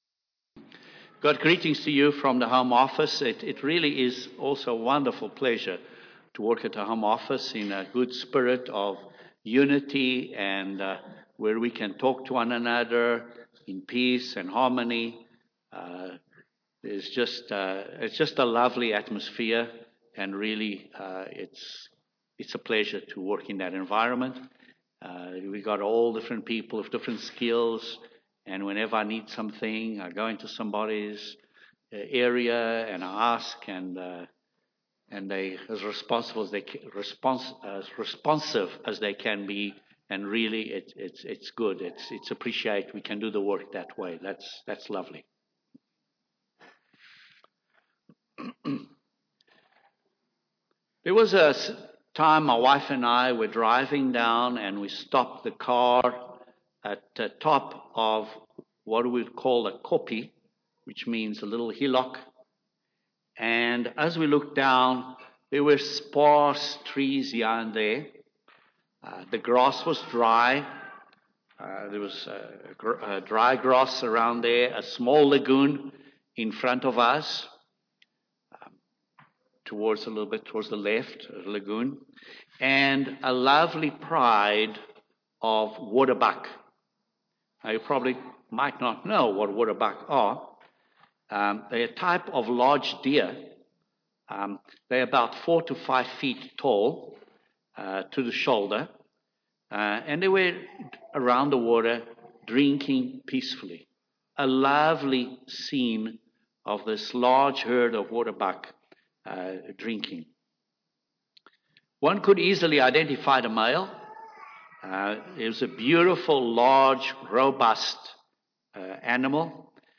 This sermon was given at the Tacoma Family Weekend, and the passion of the speaker helped to convey the lessons of vision, being ready, and having a sense of urgency.